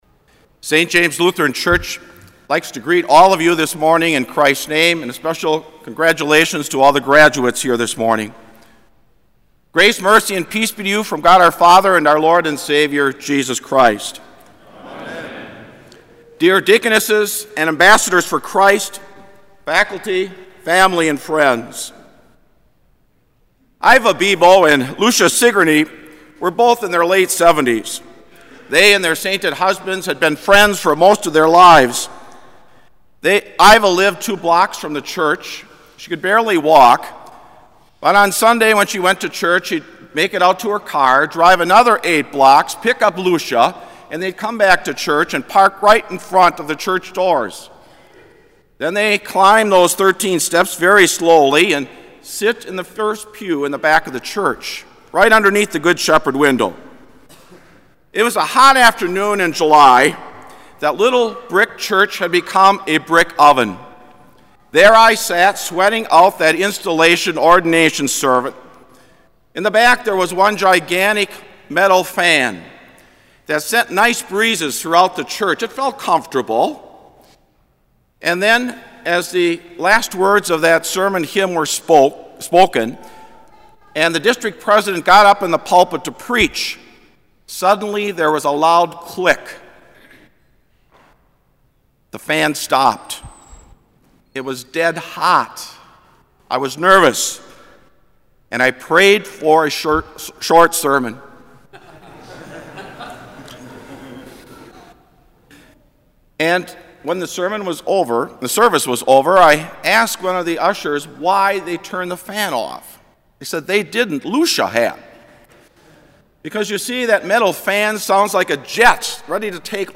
Kramer Chapel Sermon - May 18, 2007